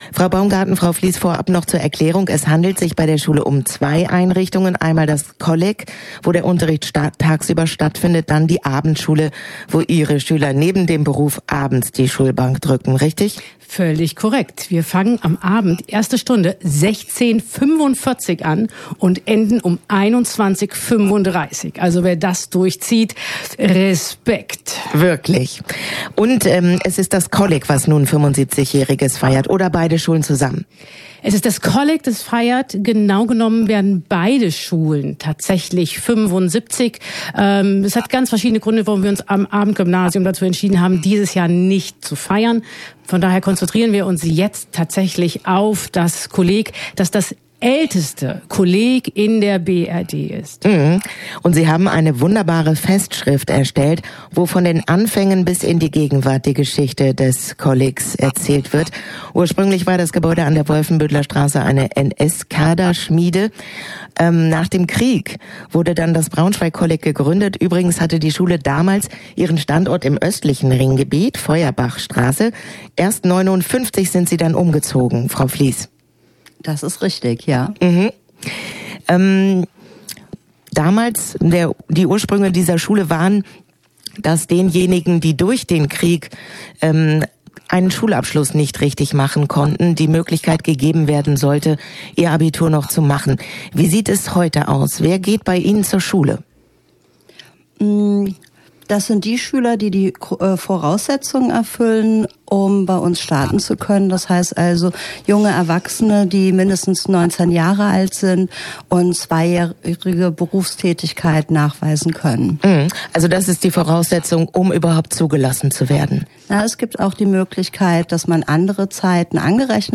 Interview-Kolleg_nb.mp3